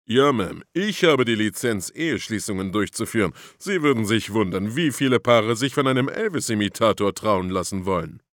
So Blonde Sample 1 Datei herunterladen weitere Infos zum Spiel in unserer Spieleliste Beschreibung: Comedian Tetje Mierendorf vertont den schrulligen Elvis in So Blonde – der auf der Vergessenen Insel tatsächlich wie ein früherer Vorfahre des King of Rock’n’Roll wirkt und genau so eigentümlich spricht.